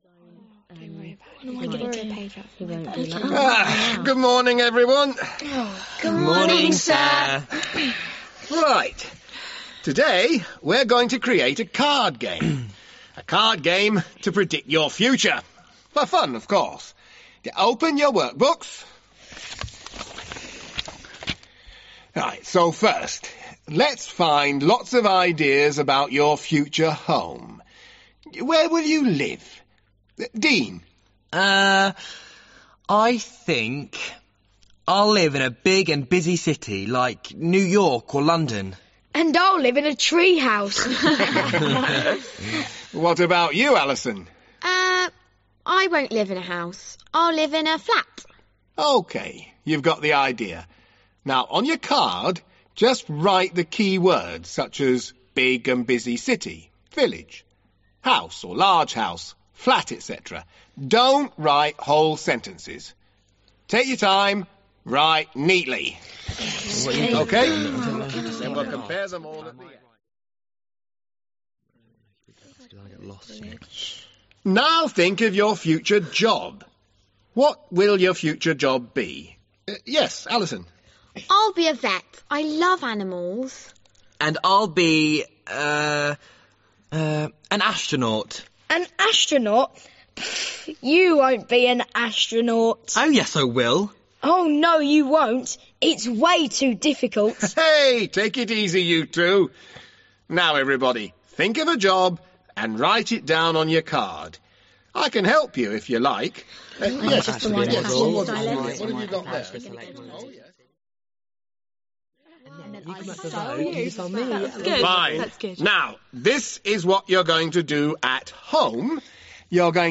The characters are a teacher, Dean, Mark and Alison.